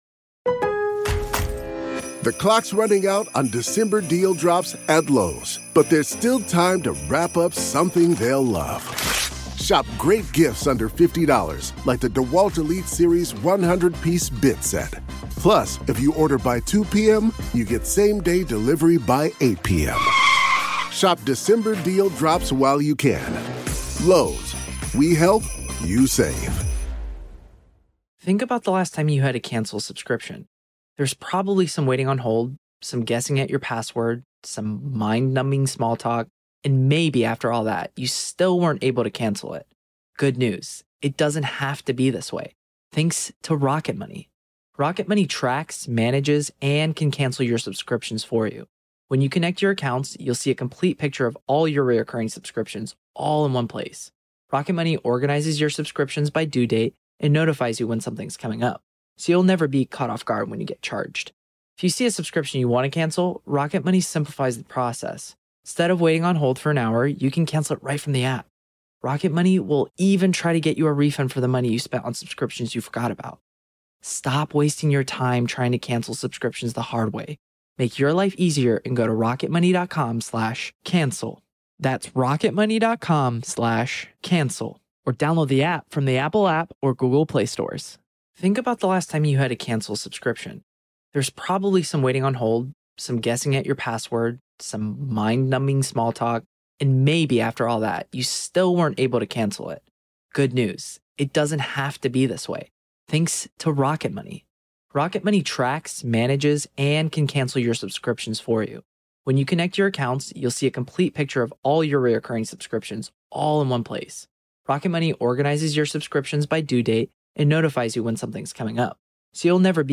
Welcome to a new episode of the Hidden Killers Podcast, where we bring you live courtroom coverage of some of the most gripping and heart-wrenching cases.